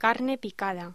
Locución: Carne picada
voz